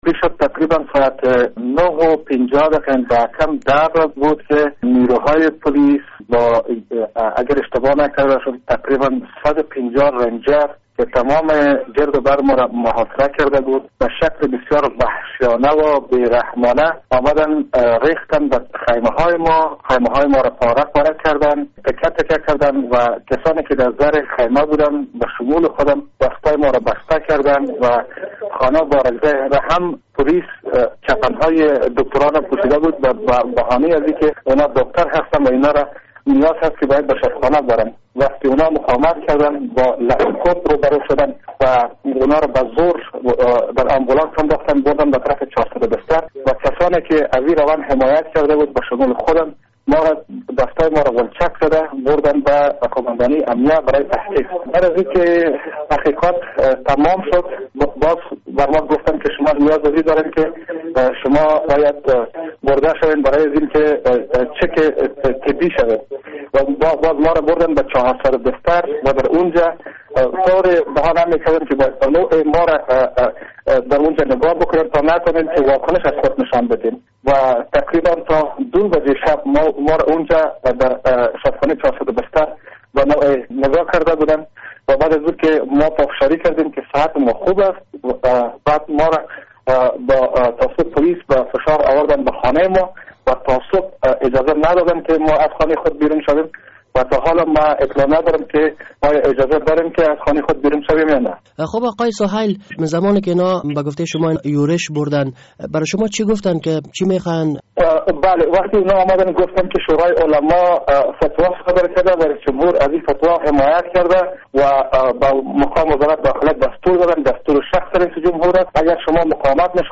مصاحبه در مورد یورش پولیس بر خیمهء سیمین بارکزی